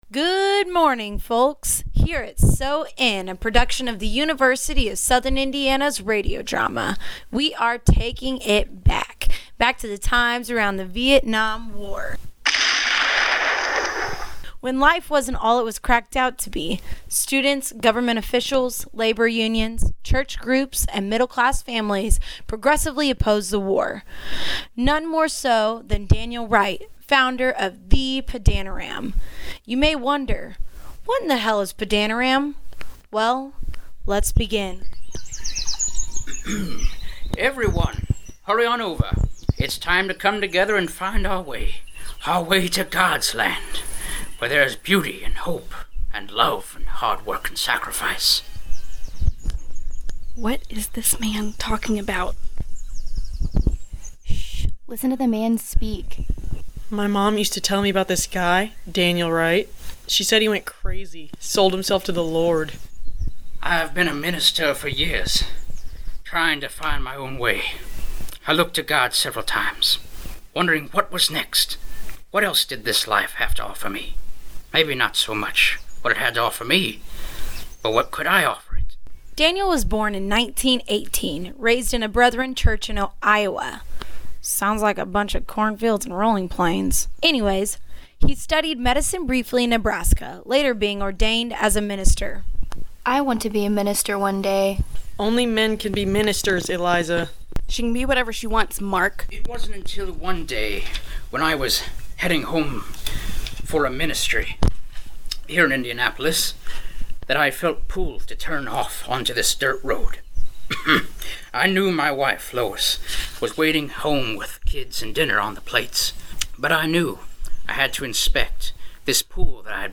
Padanaram Radio Drama